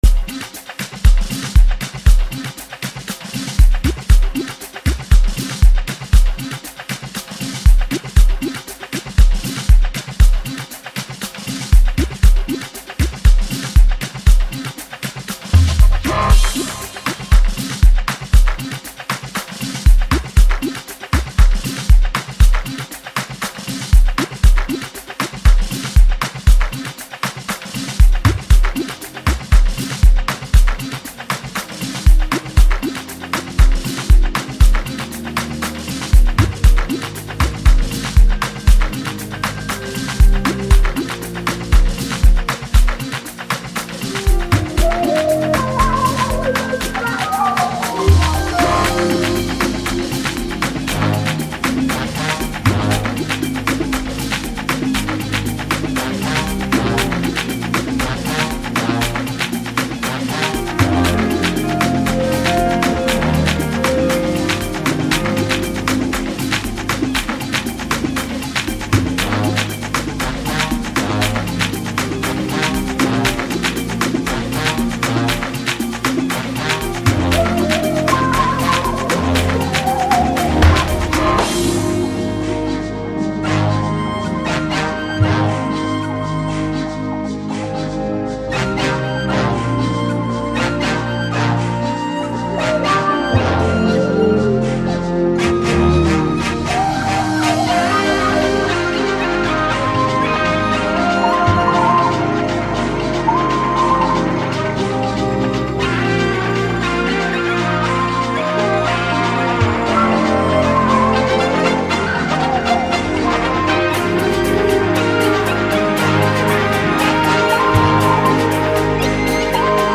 heartwarming recording